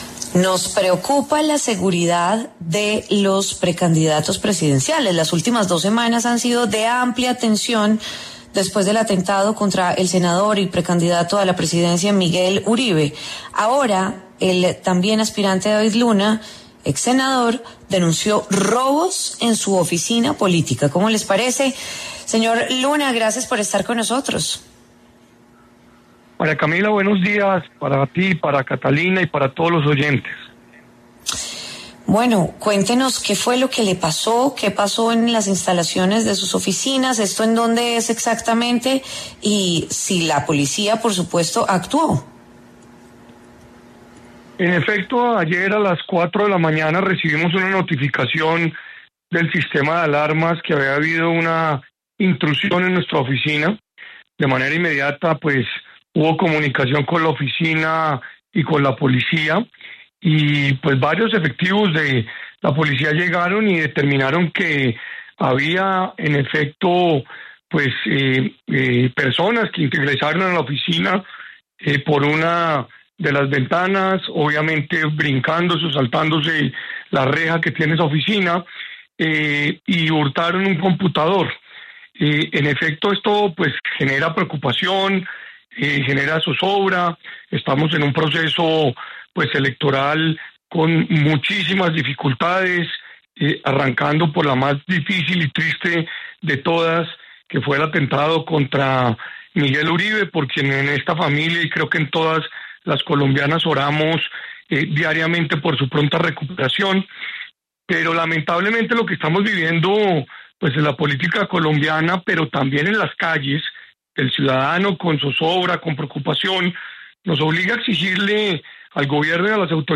El también exsenador calificó el robo como un hecho no aislado y, por esta razón, pasó por los micrófonos de W Fin de Semana, para relatar lo sucedido.